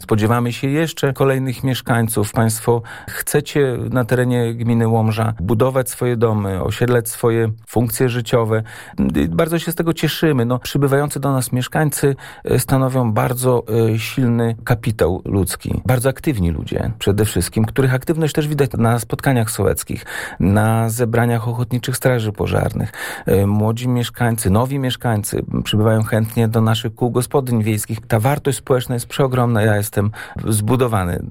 Na antenie Radia Nadzieja, wójt gminy Piotr Kłys przyznał, że to budujące.